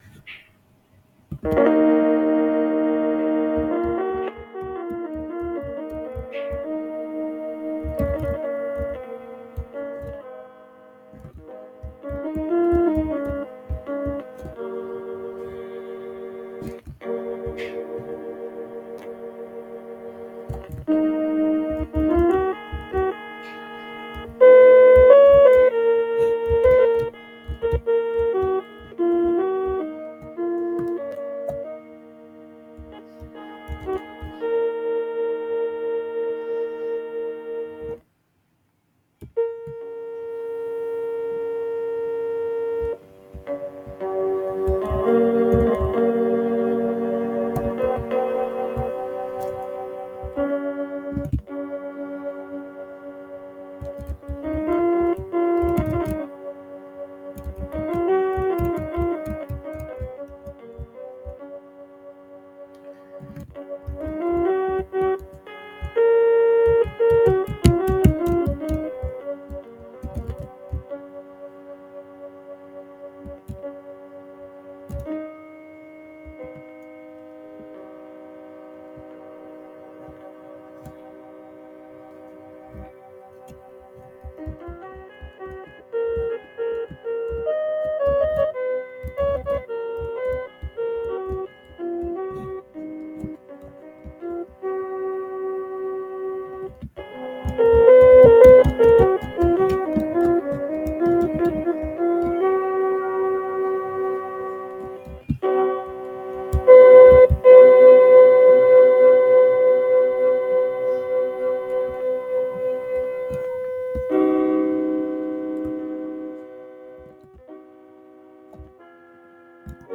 10 equal divisions of 8/3 (abbreviated 10ed8/3) is a nonoctave tuning system that divides the interval of 8/3 into 10 equal parts of about 170 ¢ each.
10ed8/3 can be seen as a very compressed version of 7edo. The octave compression results in a more accurate perfect fourth, at the expense of the fifth, which becomes a sharp Mavila fifth.
10ed8_3_improv.mp3